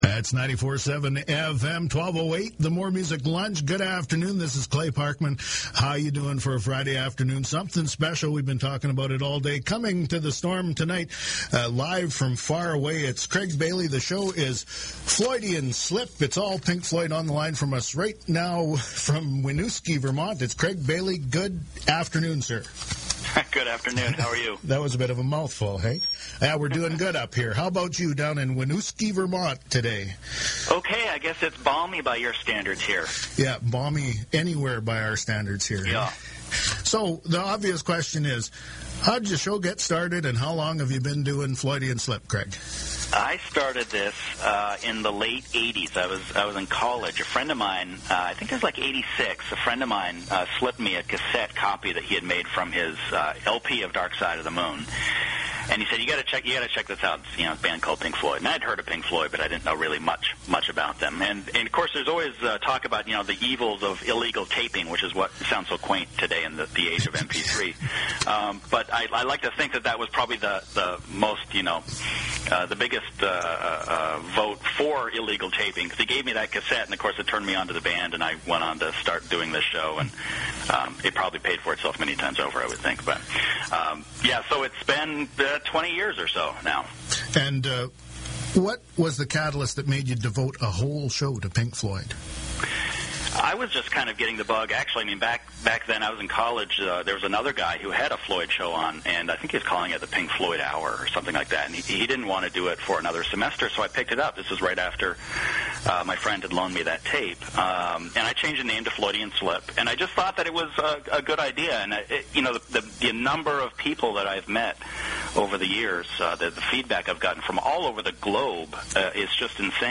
Audio: Today’s guest appearance on CJNE
CJNE broadcasts at 94.7 FM in Nipawin, SK Canada, and also streams online.